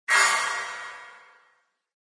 Descarga de Sonidos mp3 Gratis: apunalar 1.